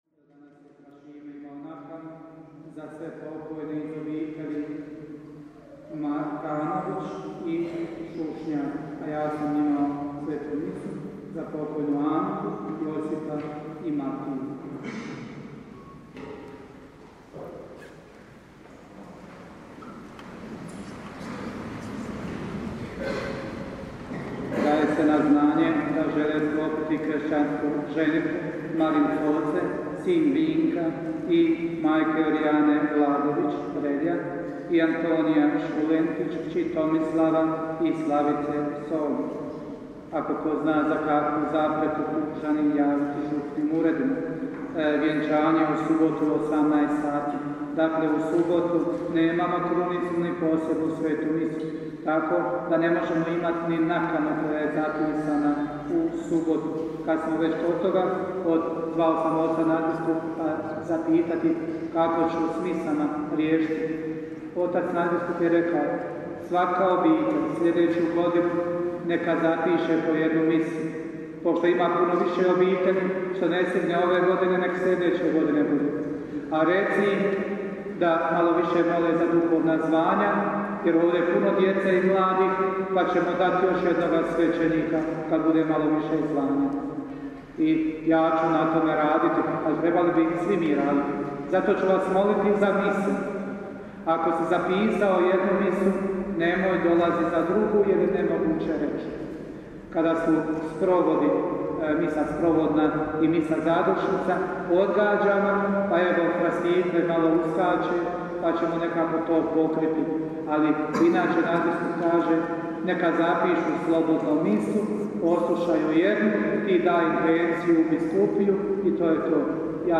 župne obavjesti (oglasi i napovjedi):